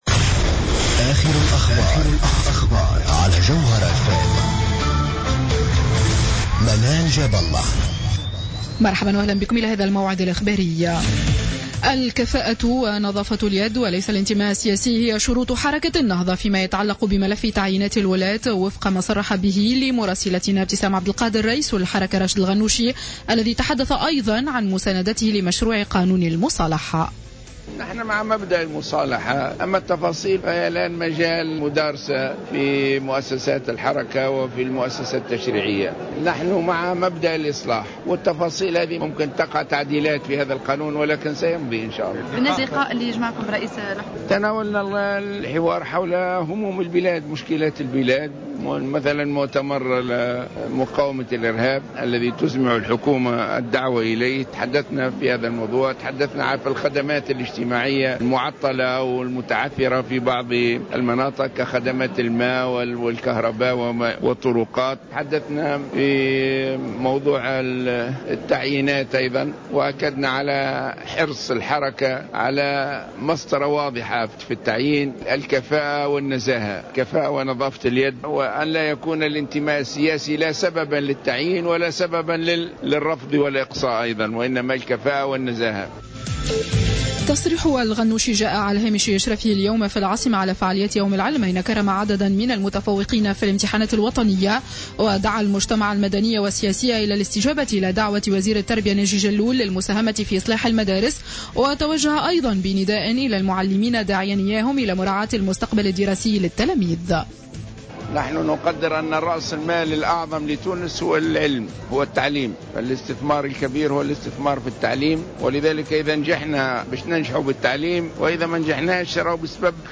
نشرة أخبار السابعة مساء ليوم الأحد 09 أوت 2015